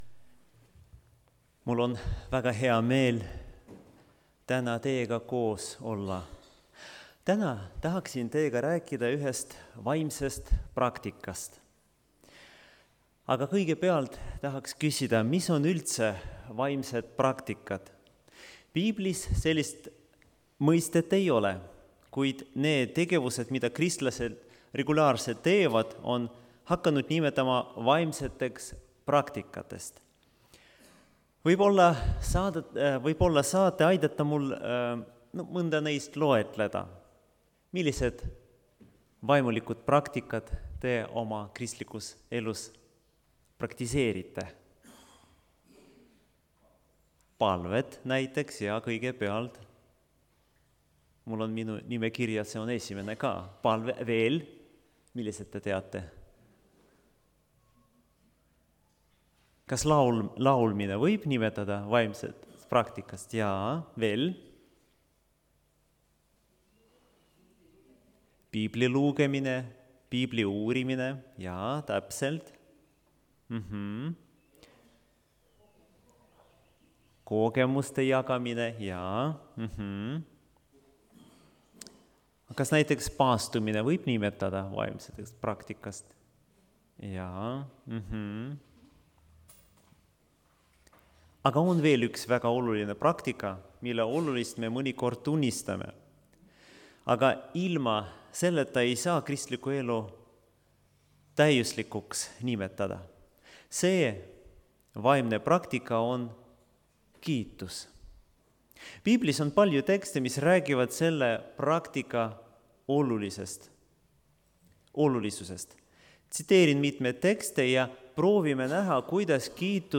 kategooria Audio / Jutlused / Teised